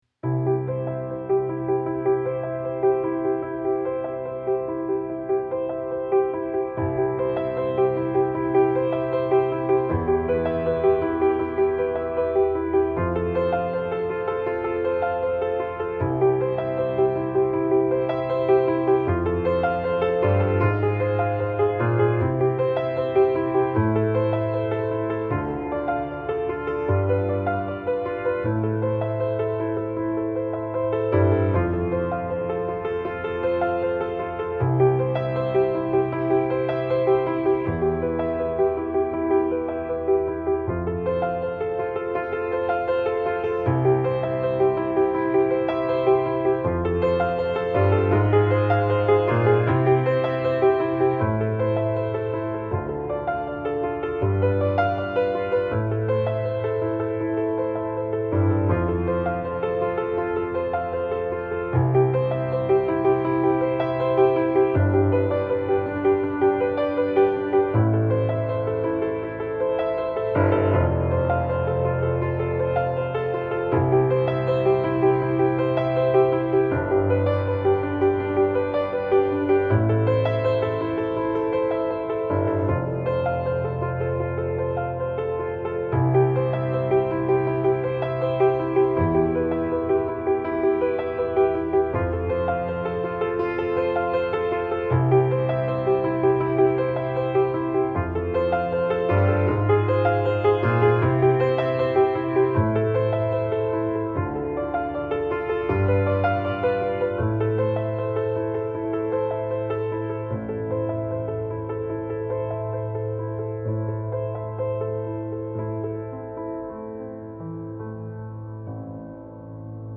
Uplifting dreamy cinematic piano theme.